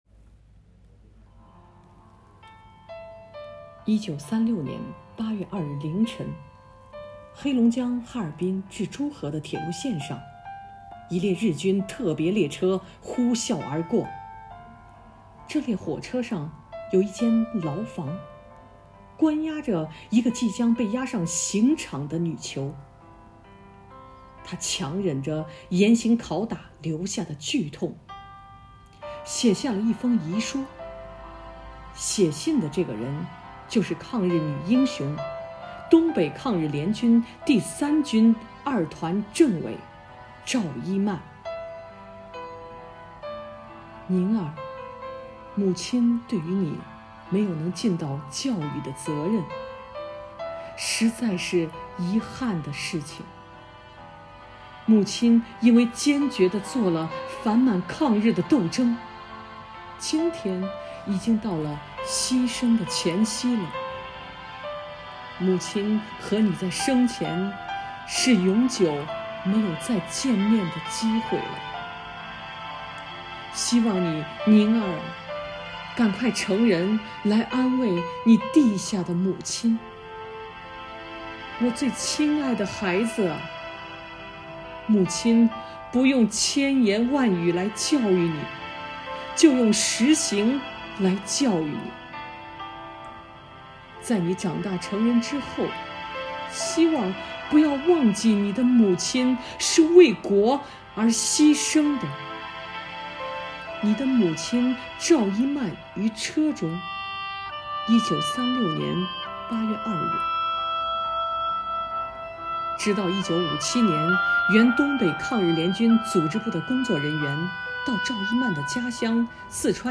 讲述人